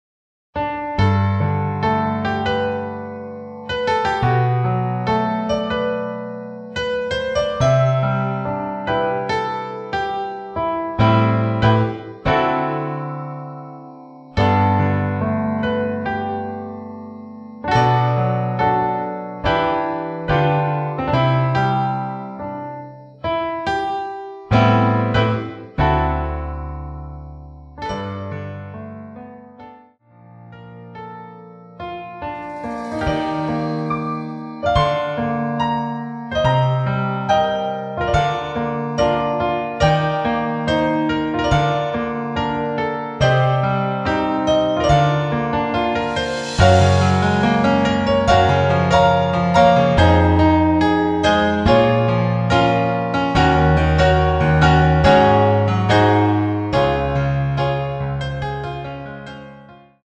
키 G 가수
원곡의 보컬 목소리를 MR에 약하게 넣어서 제작한 MR이며